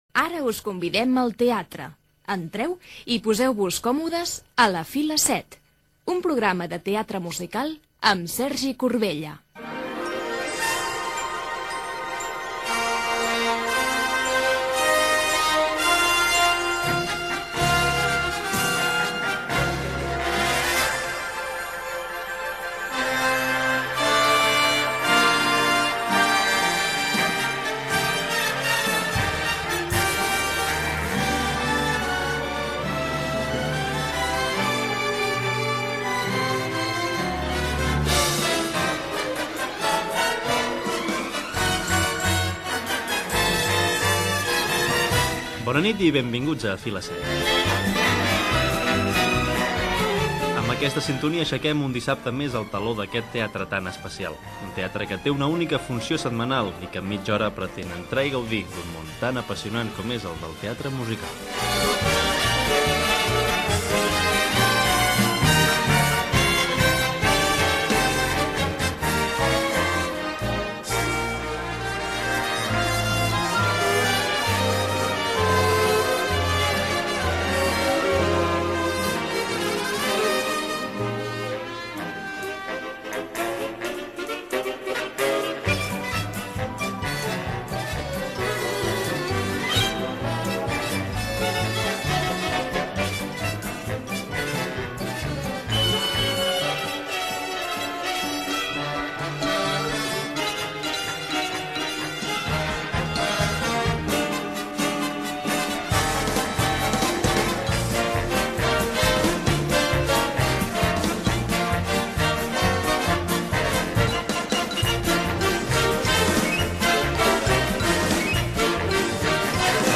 Careta del programa, presentació del programa dedicat al teatre musical, 35 anys de l'estrena de "El vionista sobre la teulada"
FM